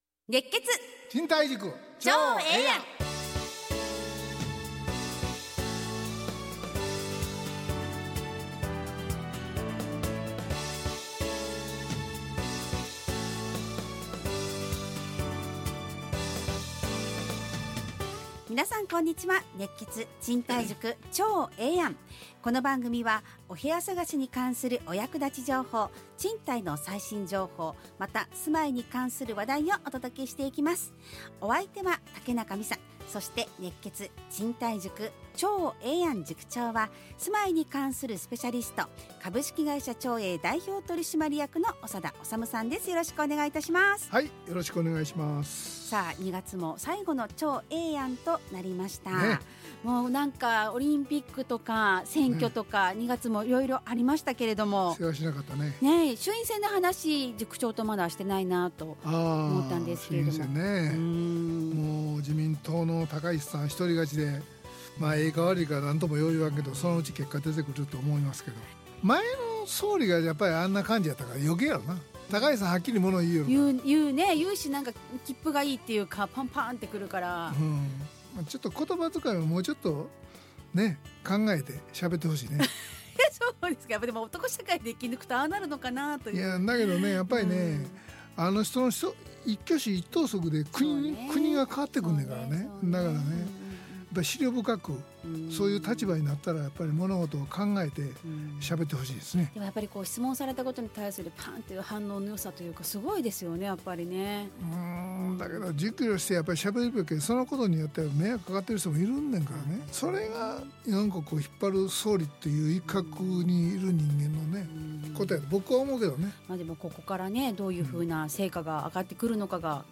ラジオ放送 2026-02-27 熱血！